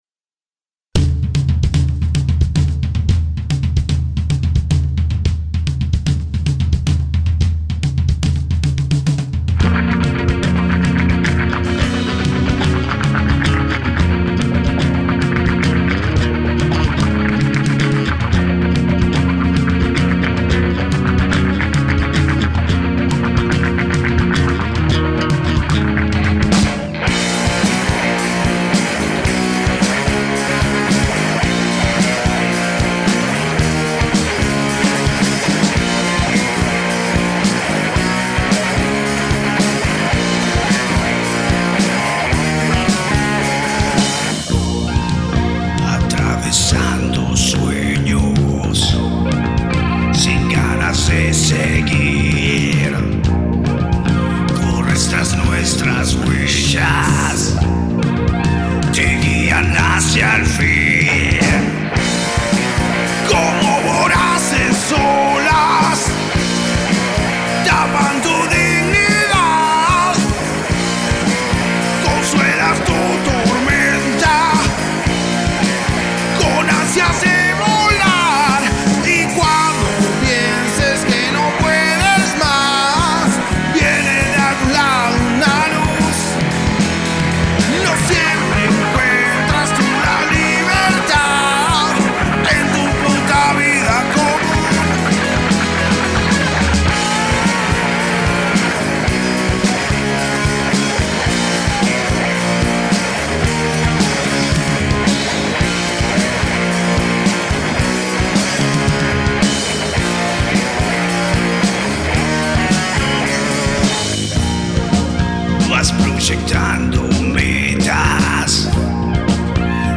voz y guitarra
bajo y coros
guitarra líder
batería.